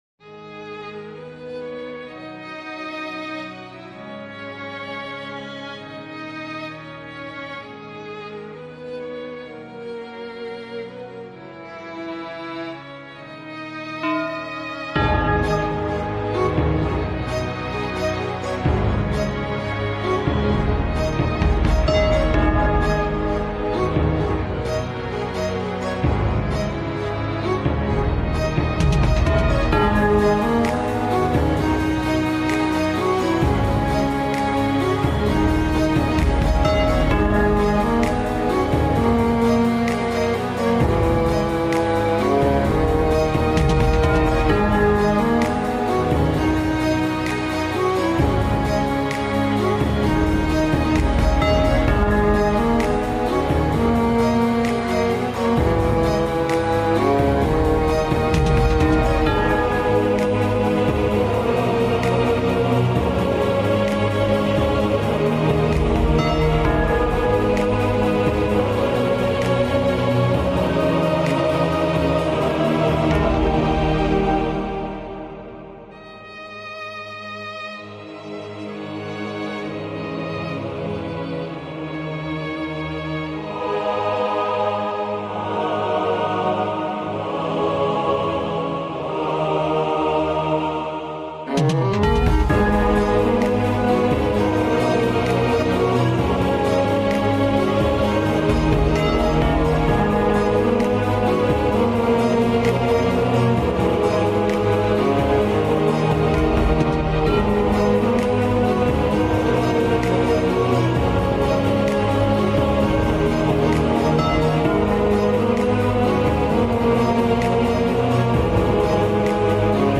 Violin.mp3